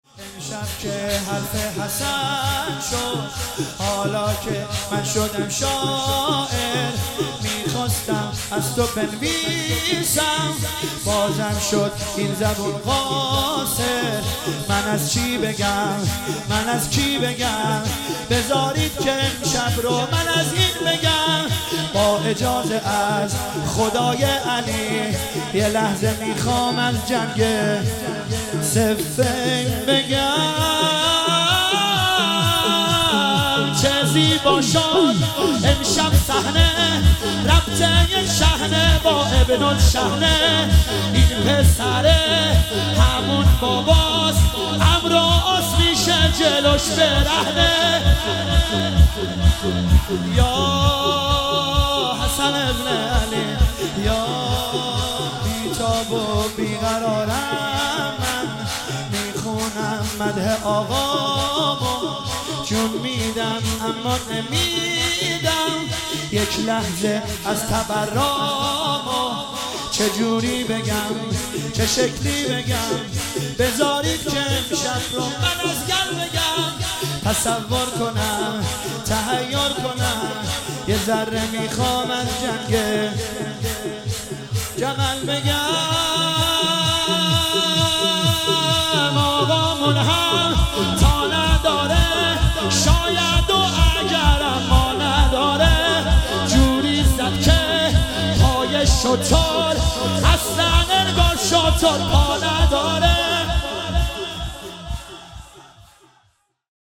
ایام محسنیه 99 | هیئت ام ابیها قم